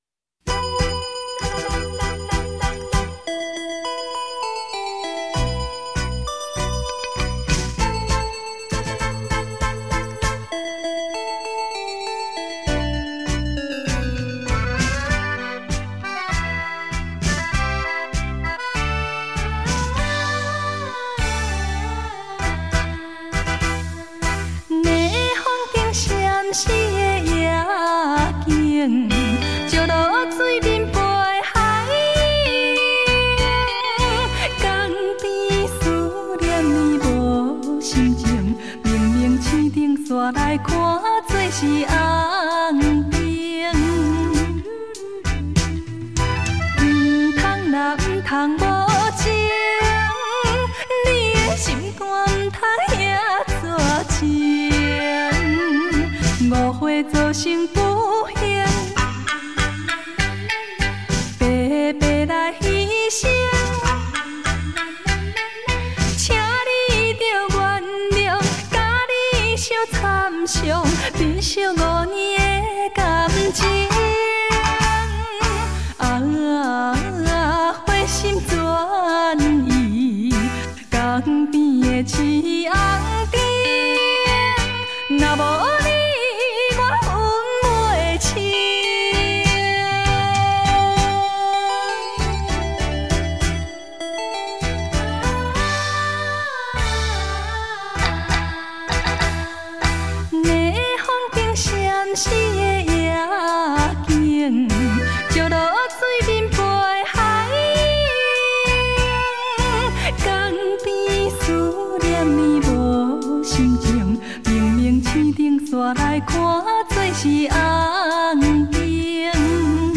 舞厅规格歌唱版
探戈歌唱版
将自己投入感性的歌声中